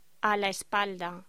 Locución: A la espalda